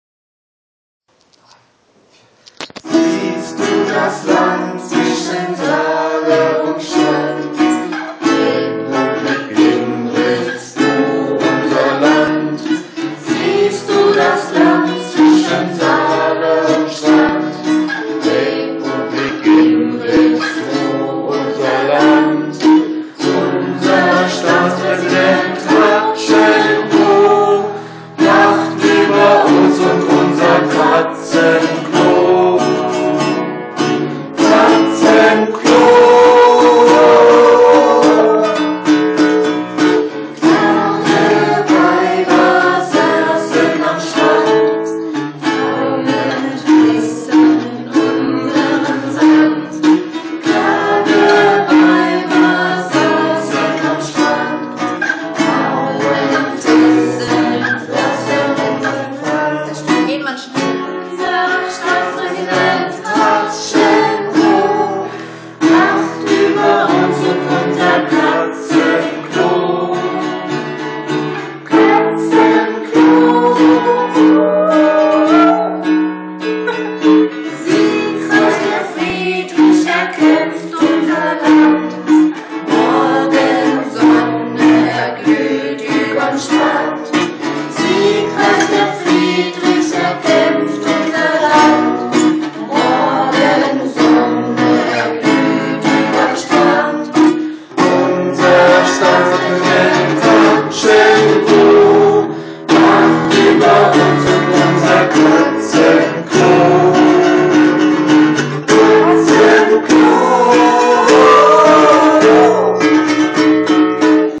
>> Die Hymne im Ausschnitt: einzigartige historische Aufnahme der Probe des Gimritzer Frauenchors, singt vor dem Kohlestabmikrophon der Ha-Chen-Ho-MP3 Registratorwalze. Wir entschuldigen uns für die schlechte Aufzeichnungsqualität...